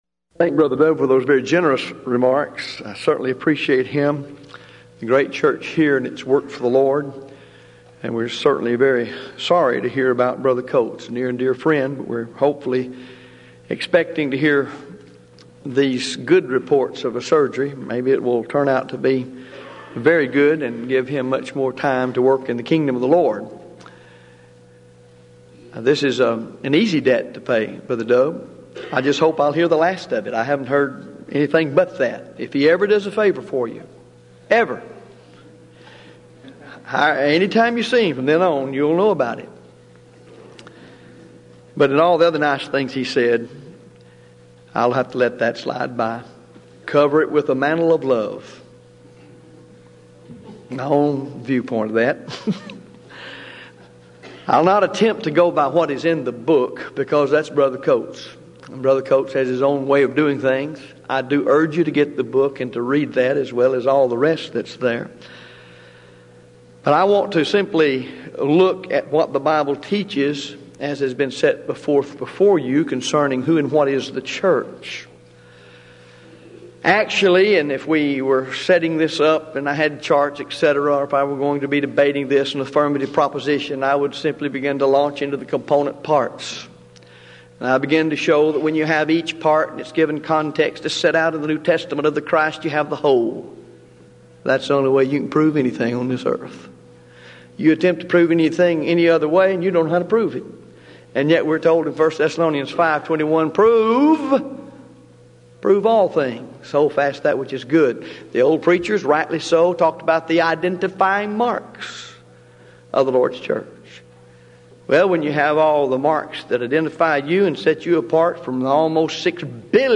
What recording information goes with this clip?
Event: 16th Annual Denton Lectures